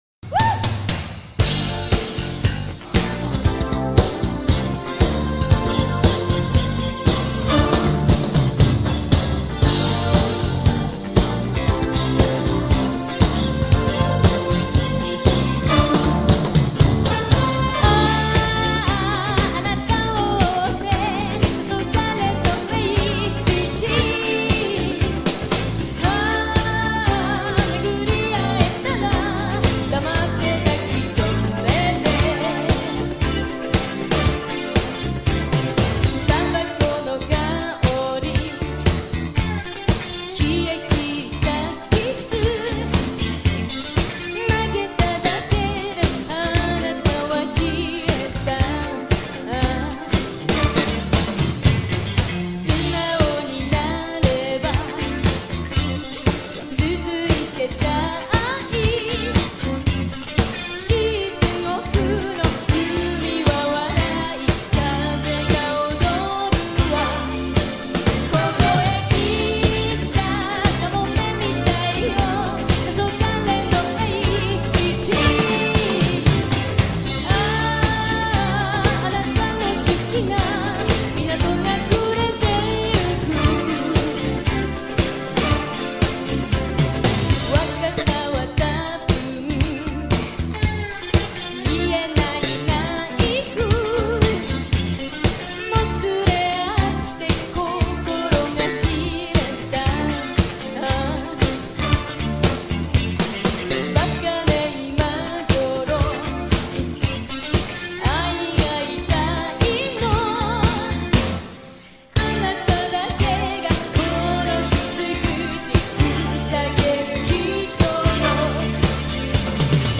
اجرای زنده